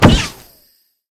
Bullet Impact 21.wav